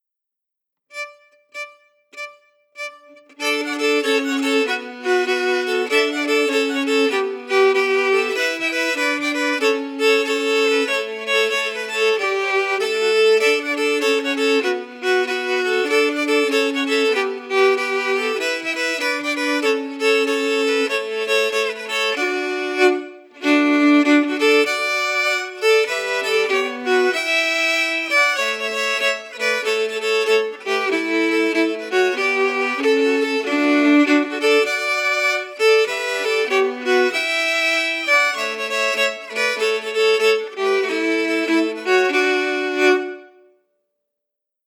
Key: D
Form: Québecois six-huit (Jig)
Genre/Style: Québecois six-huit
Quadrille-Bouchard-audio-file-harmonies.mp3